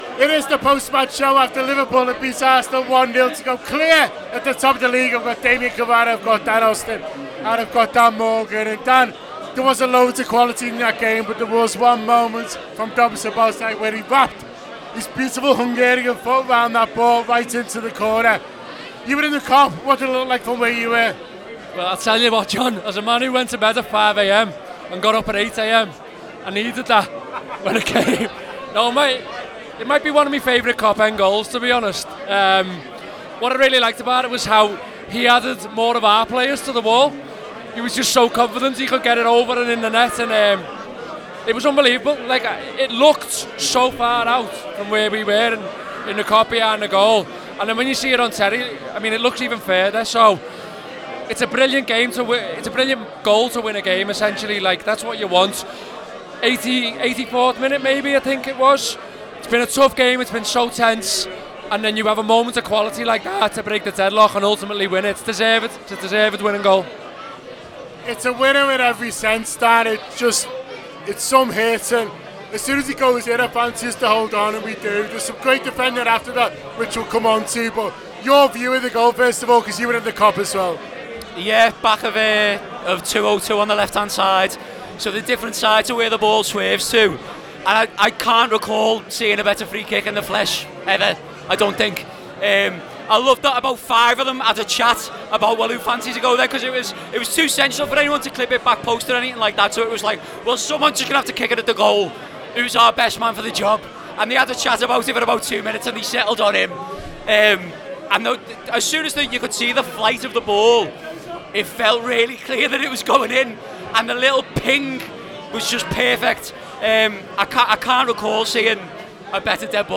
Liverpool 1-0 Arsenal: Post Match Show Reaction Podcast